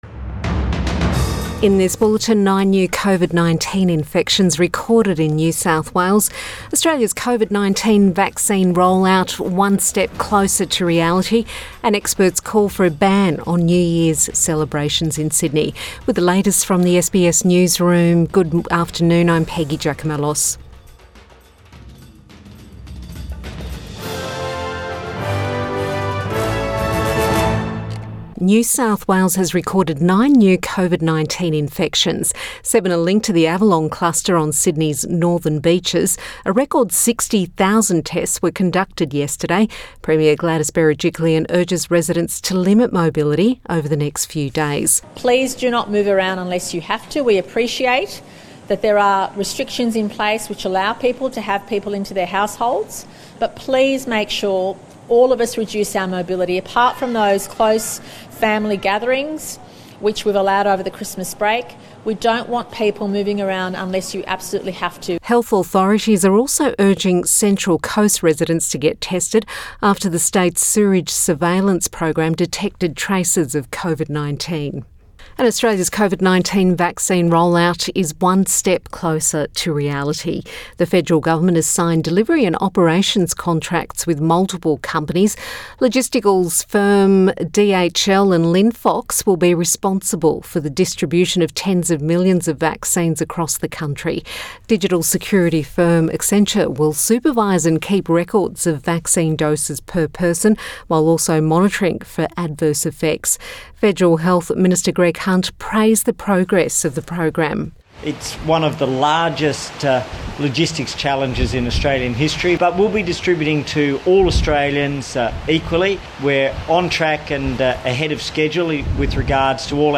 Midday bulletin 24 December 2020